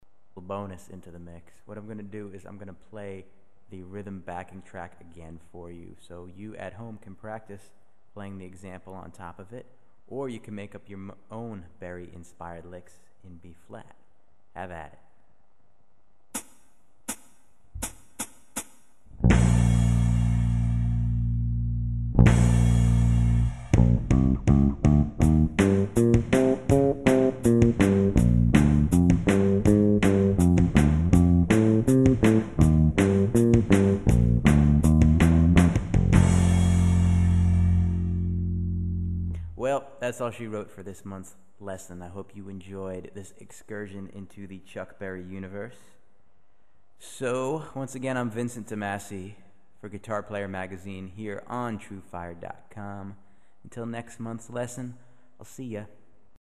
В этом отрывке представлен минус для самостоятельной игры и заключительные слова.
Минус